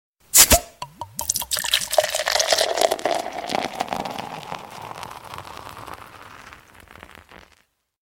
File Type : Sms ringtones